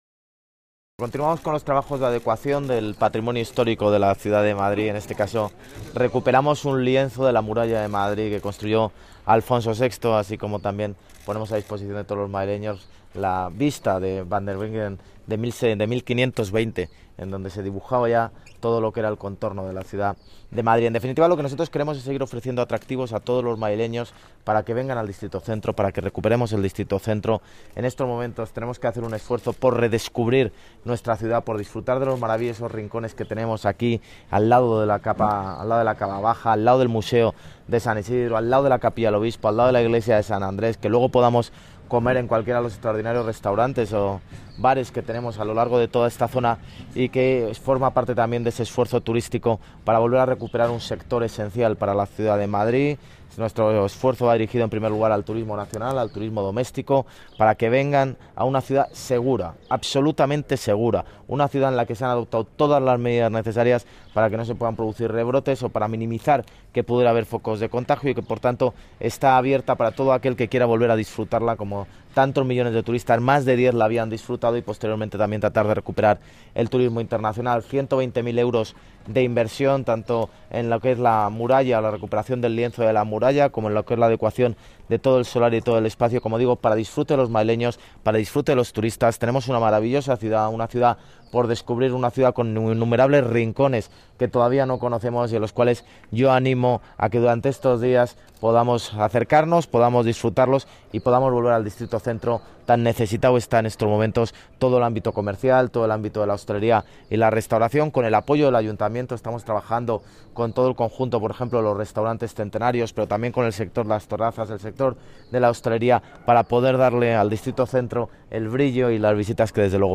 Nueva ventana:Declaraciones del alcalde en la muralla cristiana de Madrid